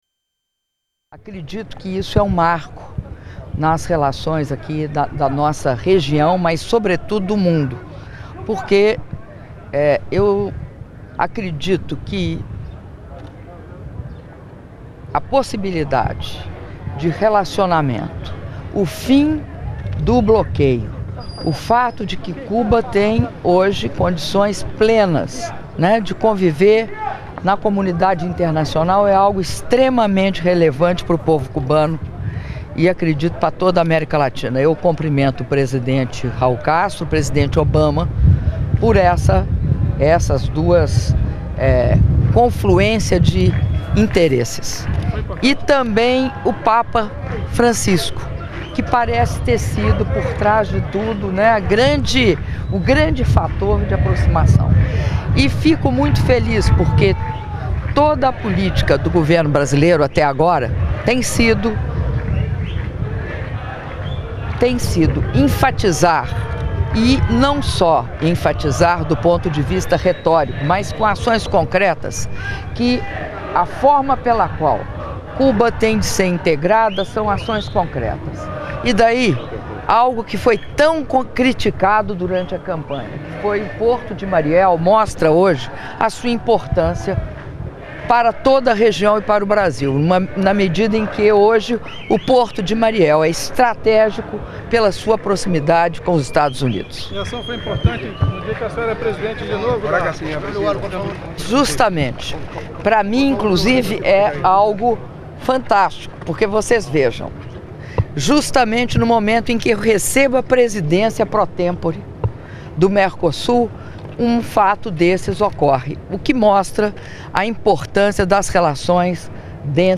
Áudio da entrevista coletiva concedida pela Presidenta da República, Dilma Rousseff, após Cerimônia de Abertura da XLVII Cúpula do Mercosul e Estados Associados - Paraná, Província de Entre Ríos/Argentina (02min03s)